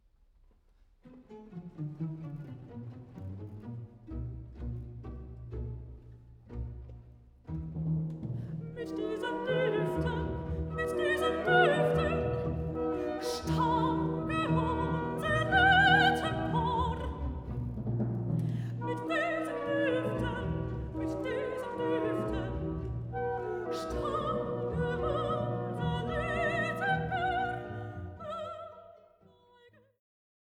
Rezitativ (Chamital)